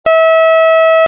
Ἡ διάρκεια τοῦ κάθε φθόγγου εἶναι 1 δευτερόλεπτο.
Πα = 288Hz
Κλίμακα Πα-Πα'
Οἱ ἤχοι ἔχουν παραχθεῖ μὲ ὑπολογιστὴ μὲ ὑπέρθεση ἀρμονικῶν.